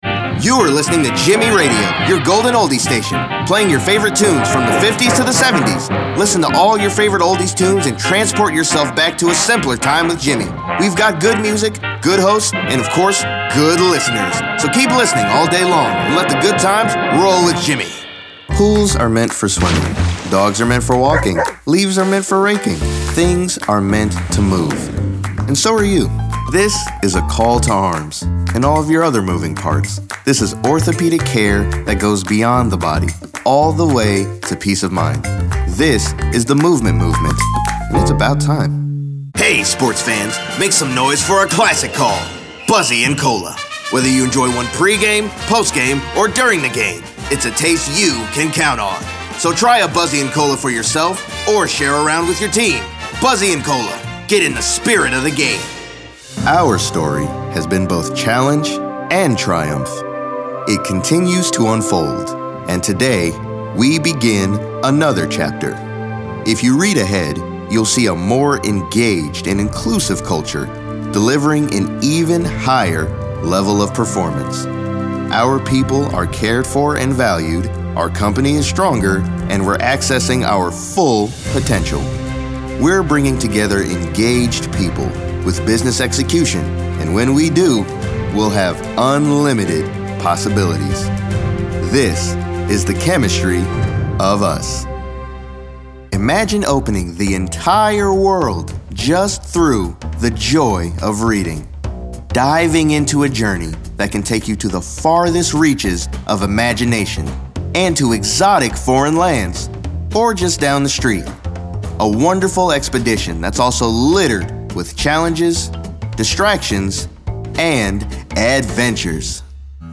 A fresh and friendly voice for your next project!
announcer, attitude, caring, character, confessional, confident, conversational, friendly, genuine, guy-next-door, inspirational, motivational, perky, serious, thoughtful, upbeat